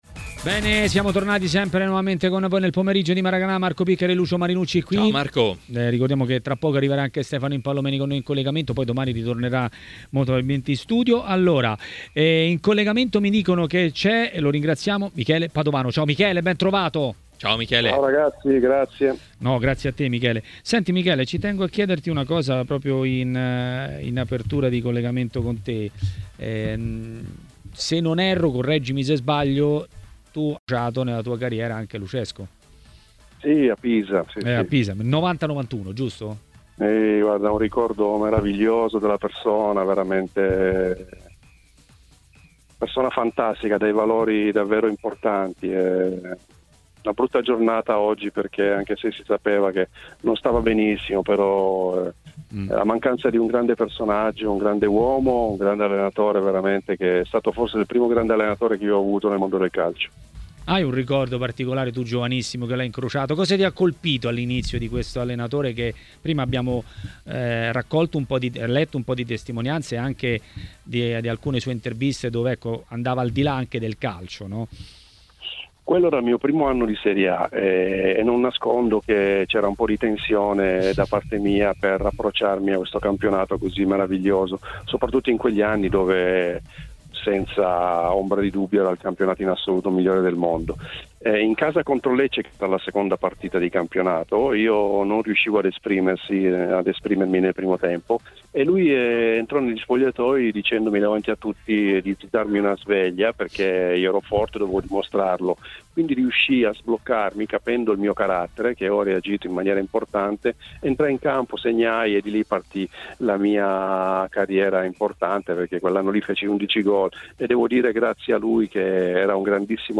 Ospite di Maracanà, nel pomeriggio di TMW Radio, è stato l'ex calciatore Michele Padovano.